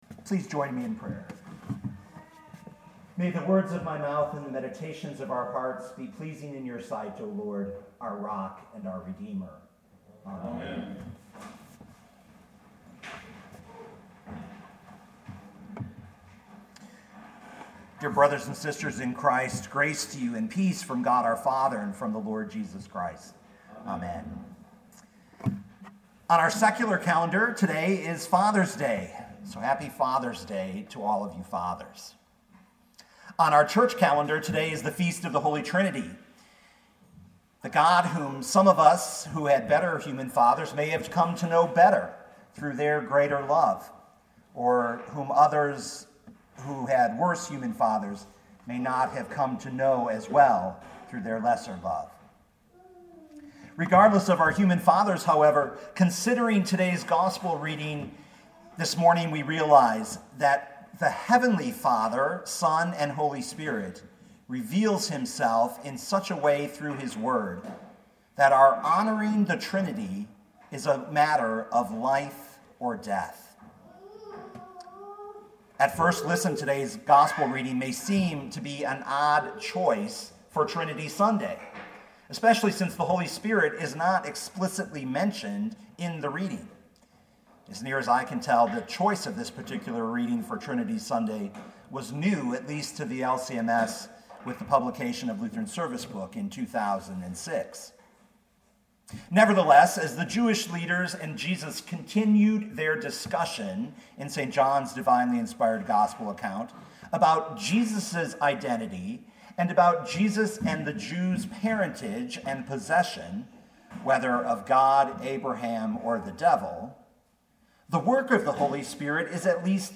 2019 John 8:48-59 Listen to the sermon with the player below, or, download the audio.